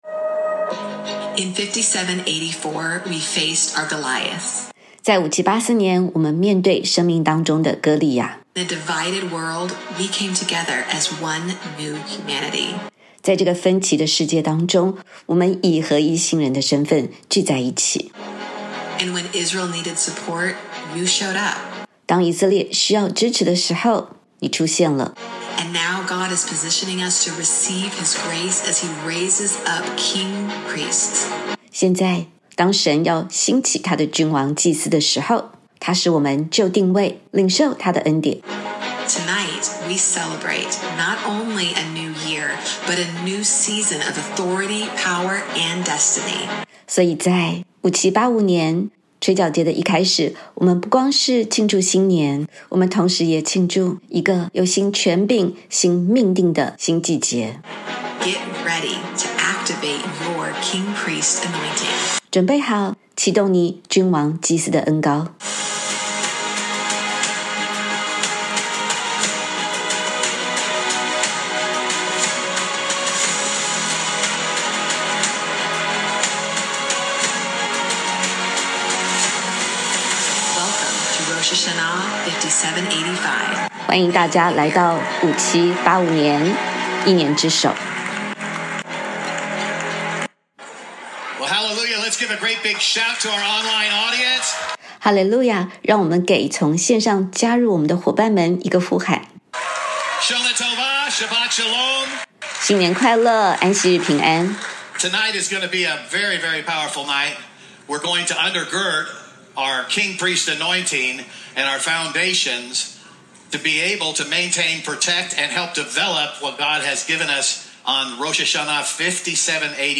本篇感谢大卫之家 允诺口译并授权微牧刊登